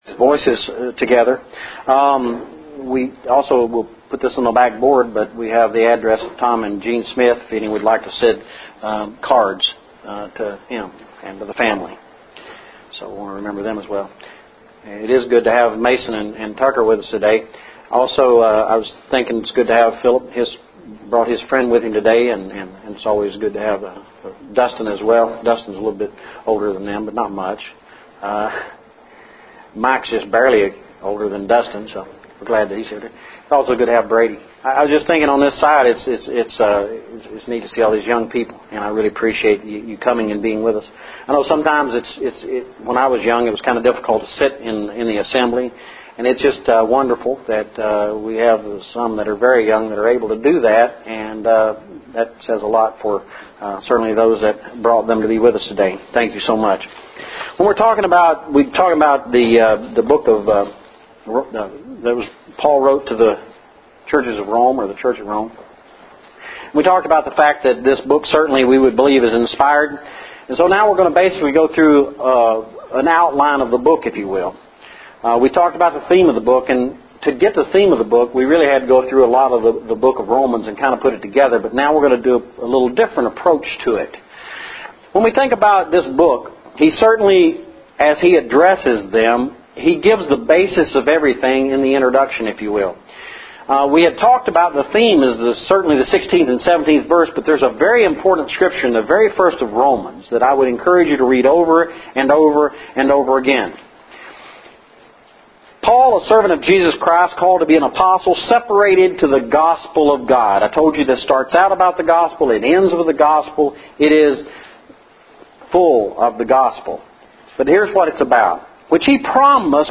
The Book of Romans Lesson – 02/05/12